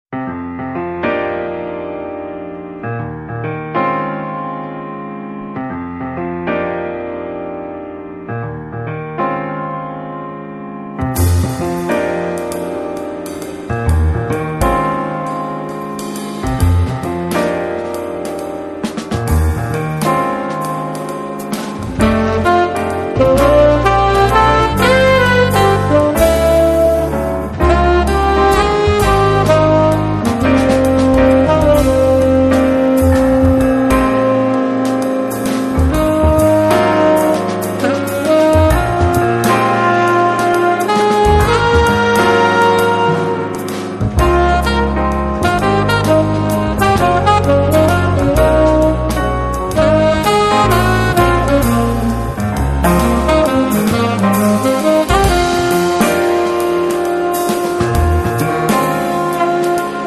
sax contralto
sax tenore
piano
contrabbasso e basso elettrico
batteria
una sorta di fusion acustica